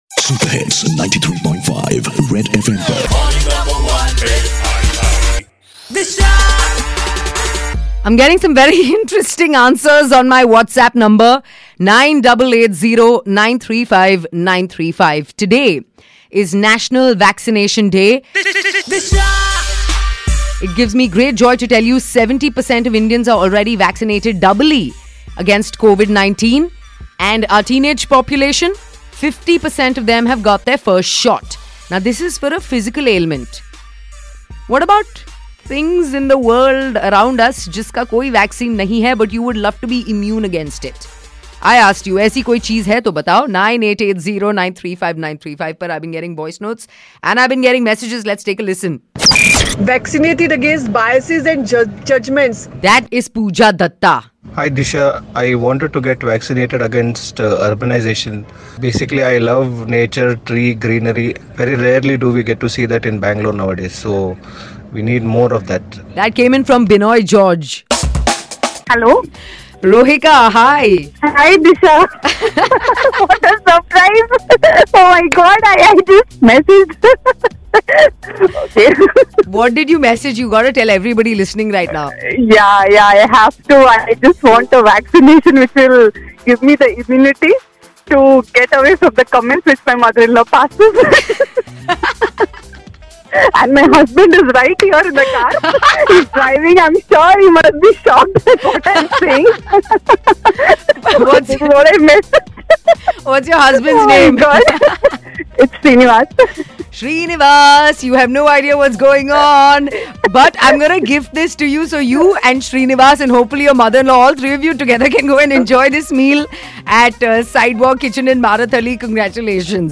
What would you want to get vaccination from? Tune in to listen what our listeners had to say!